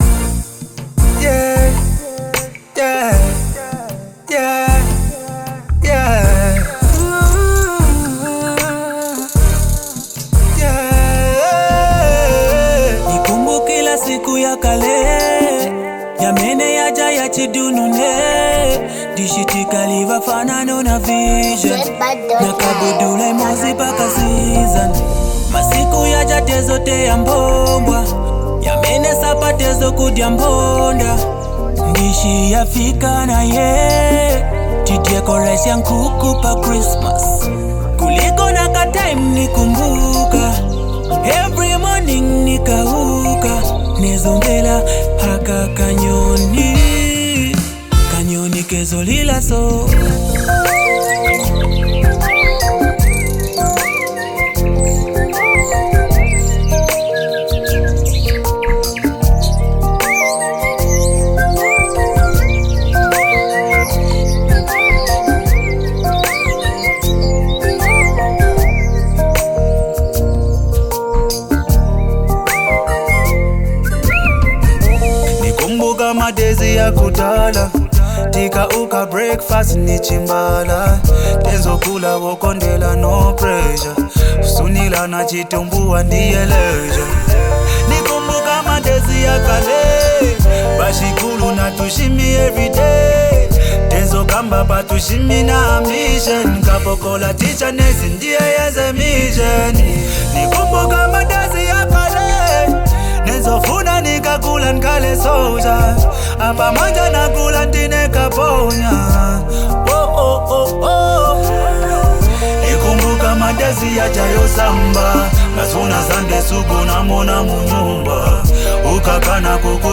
Category: Zambian Music